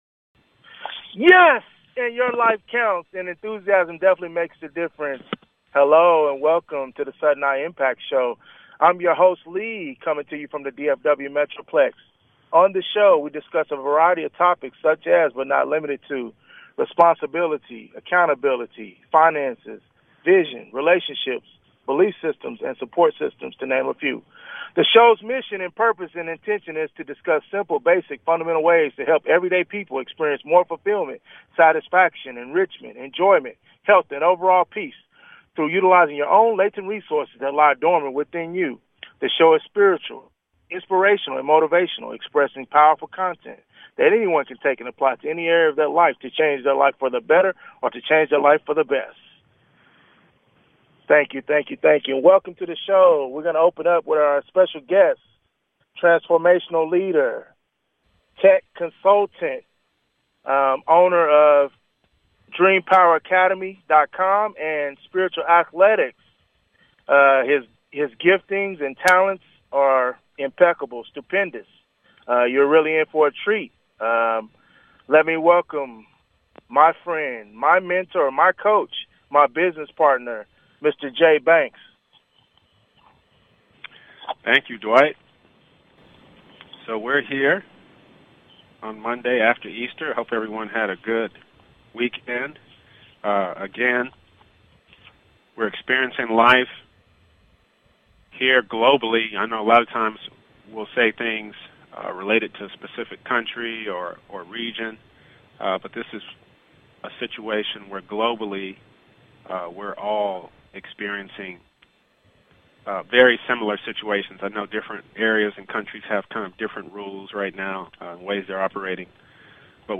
Sudden I Impact (sii) is a talk show about discovery, helping people raise their awareness and identify their gifts, finding things in life they enjoy doing, finding their uniqueness, and potentially turning their passions into businesses that thrive, and most of all, living life by plan and design to earn a living doing what they love (the essential thing).